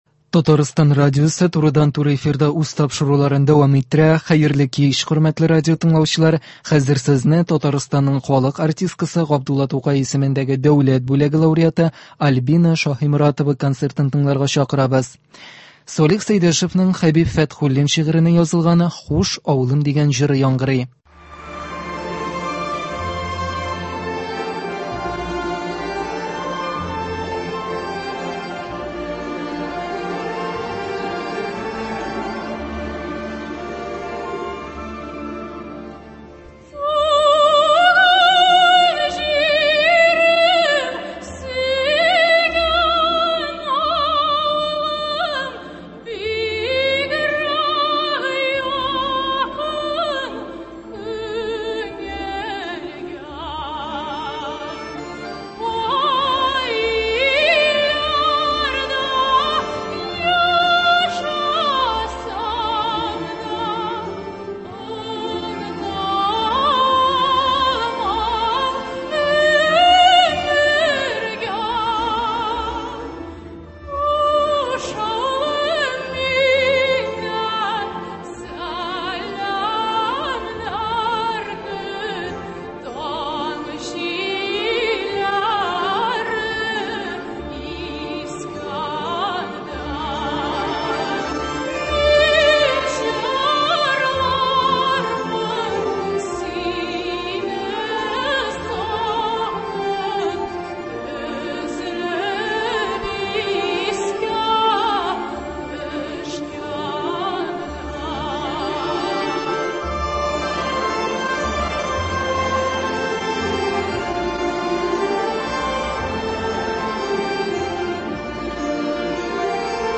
Яшь башкаручылар концерты. Альбина Шаһиморатова җырлый.